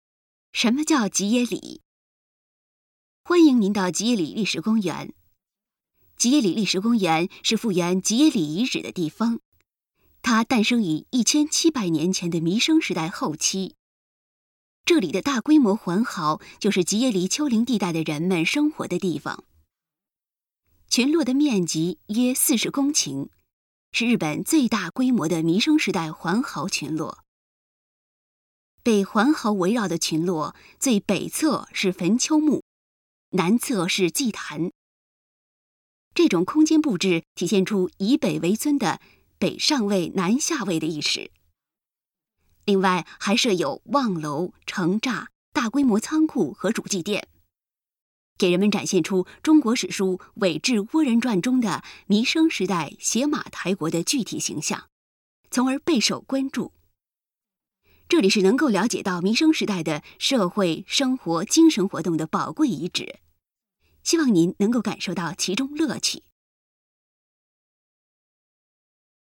语音导览 下一页 返回手机导游首页 (c)YOSHINOGARI HISTORYCAL PARK